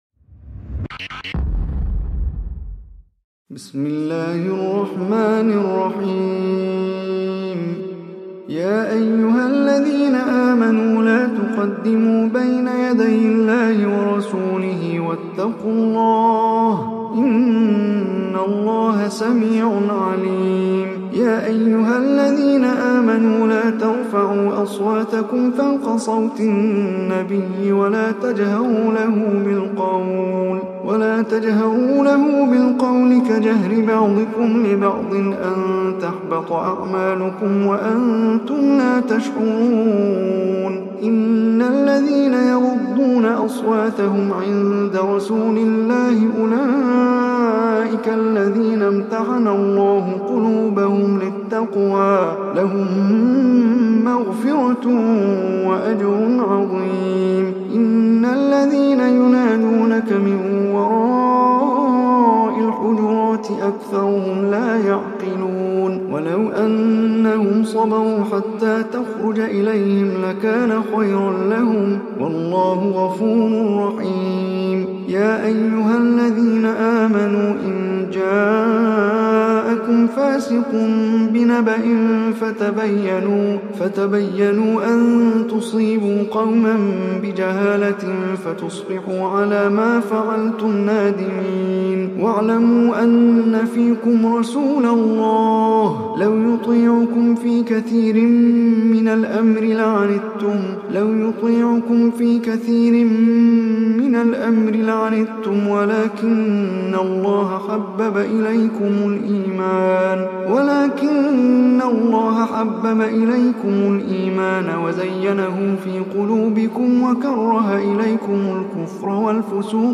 Surah Al-Hujurat Recitation by Omar Hisham Arabi
Surah Al-Hujurat, listen or play online mp3 tilawat / recitation in Arabic in the beautiful voice of Omar Hisham Al Arabi.